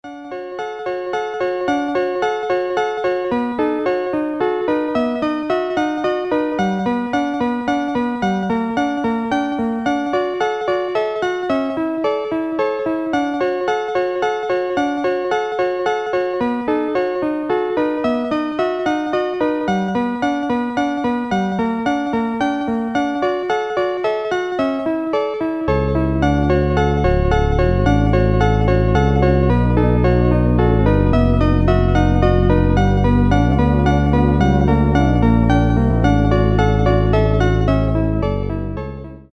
Рингтоны Без Слов
Рингтоны Электроника